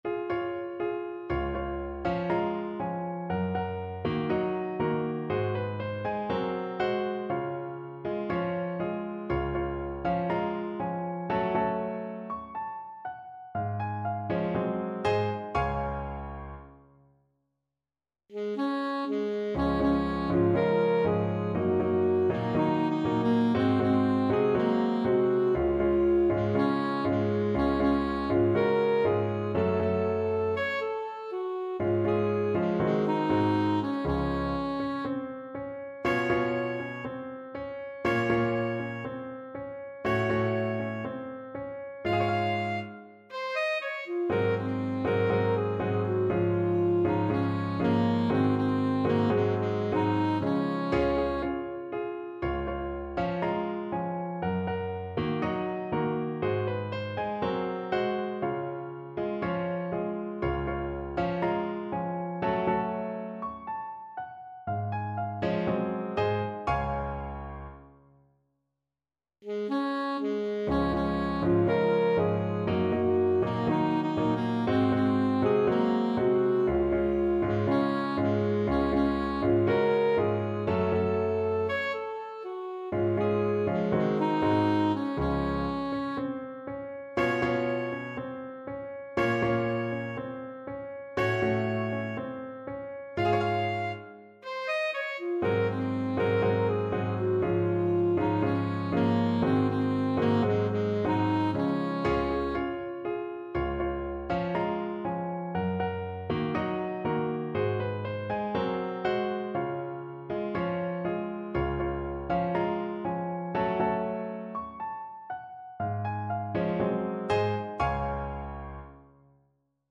Alto Saxophone version
Gracioso = 60
4/4 (View more 4/4 Music)
Classical (View more Classical Saxophone Music)